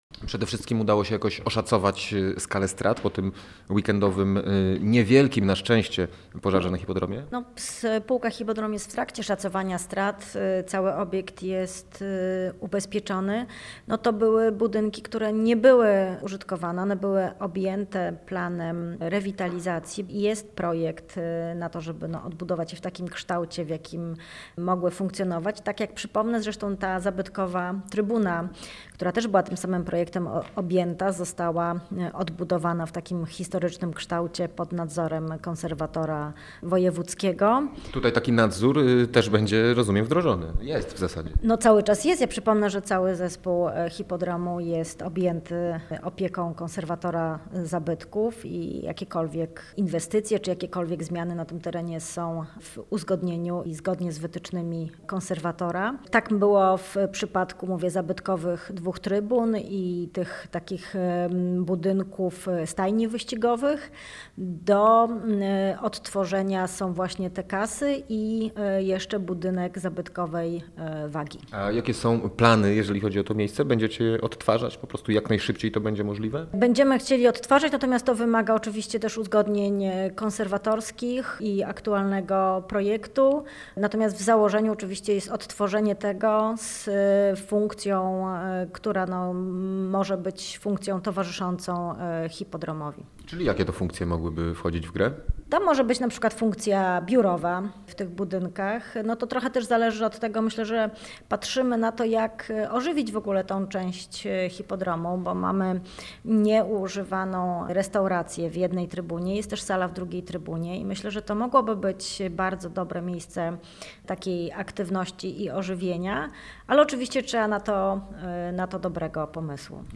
Tymczasem, jak poinformowała w rozmowie z Radiem Gdańsk Magdalena Czarzyńska-Jachim, prezydentka Sopotu, spółka Hipodrom i miasto zastanawiają się nad remontem obiektu.